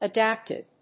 音節a・dápt・ed 発音記号・読み方
/ʌˈdæptɪd(米国英語)/
フリガナアダップティド